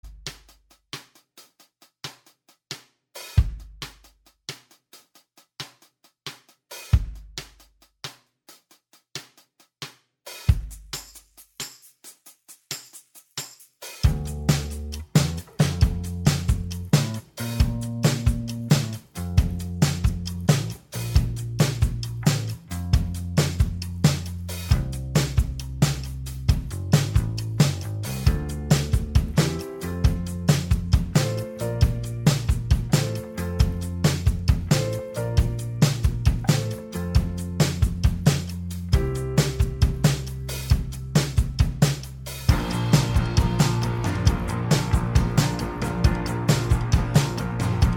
Minus All Guitars Pop (2010s) 3:44 Buy £1.50